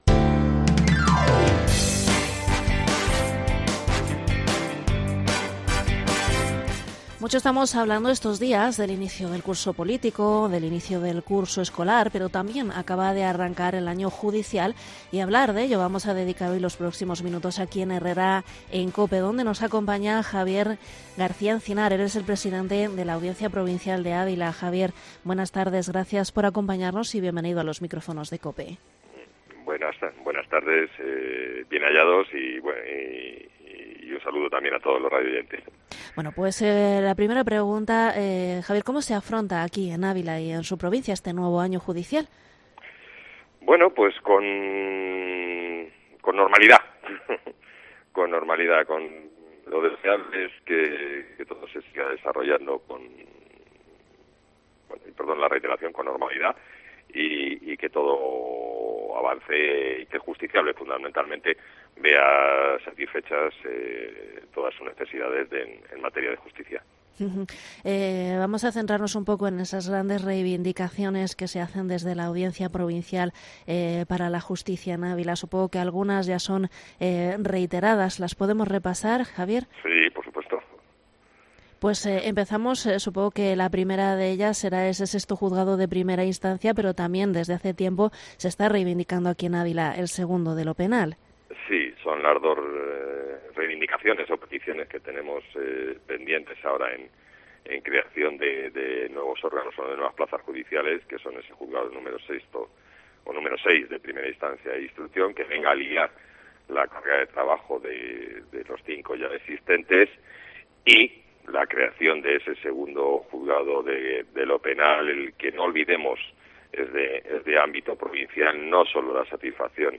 Entrevista en Cope Ávila al presidente de la Audiencia Provincial de Ávila, Javier García Encinar